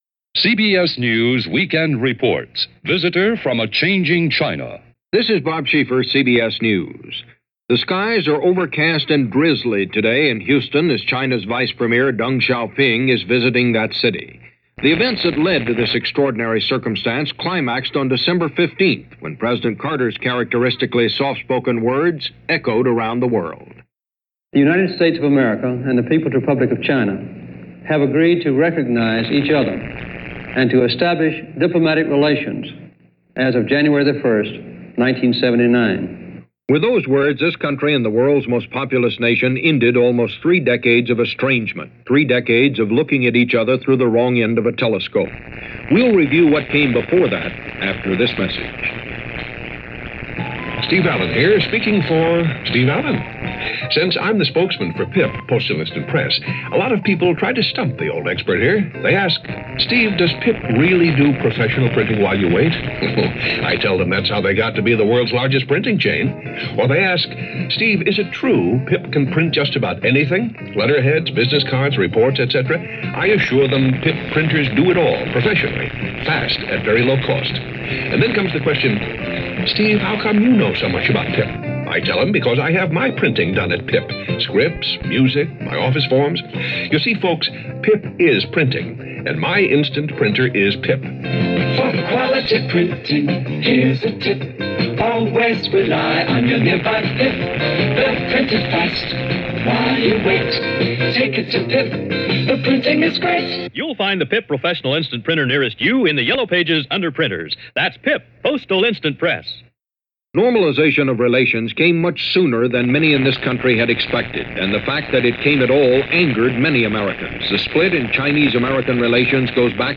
February 4, 1979 – Weekend Report – The World This Week -CBS Hourly News – Gordon Skene Sound Collection – […]